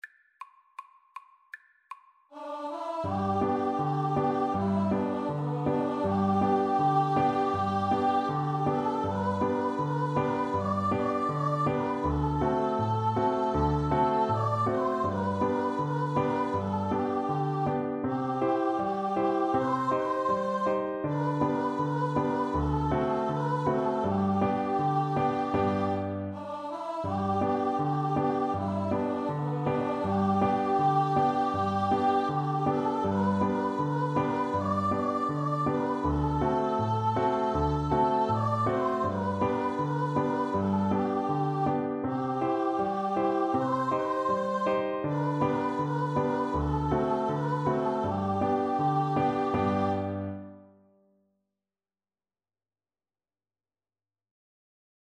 4/4 (View more 4/4 Music)
Traditional (View more Traditional Voice Music)